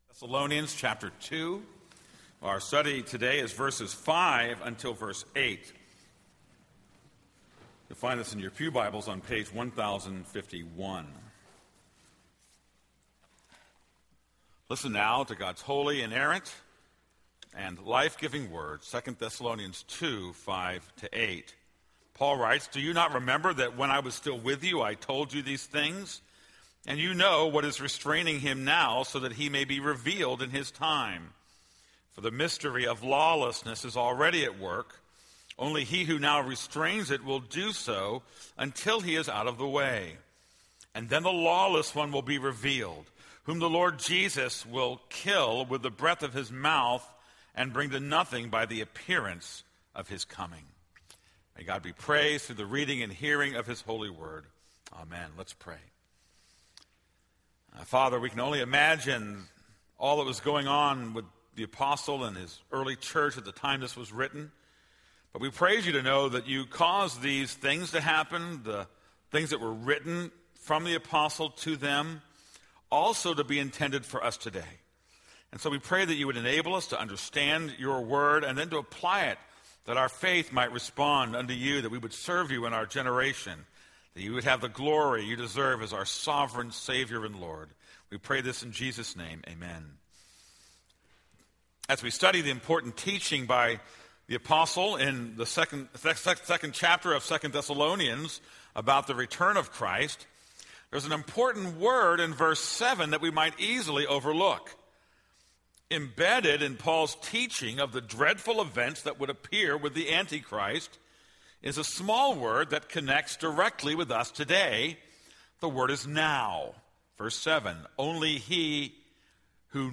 This is a sermon on 2 Thessalonians 2:3-8.